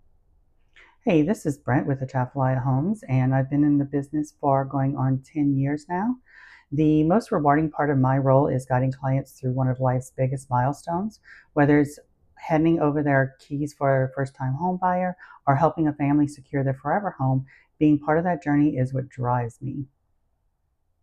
Voice Note